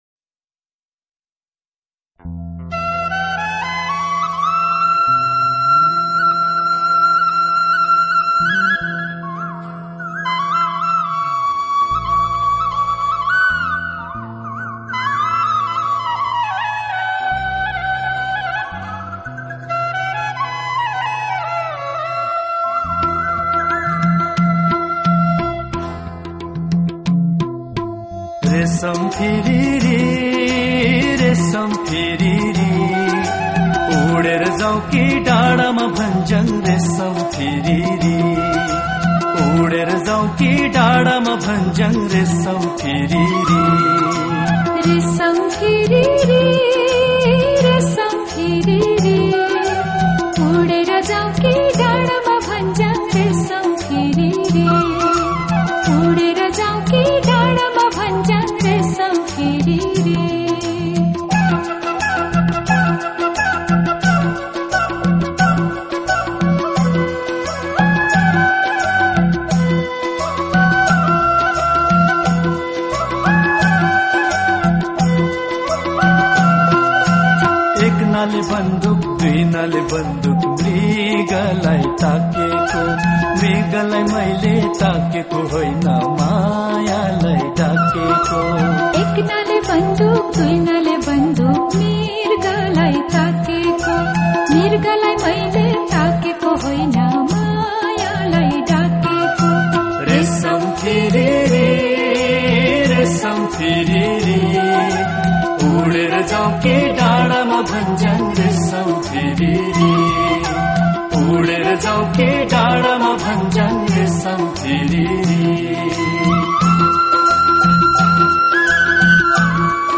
Вот здесь народная версия (около 1 Мб), а мы обычно слушали какую-то рок-н-ролльную обработку.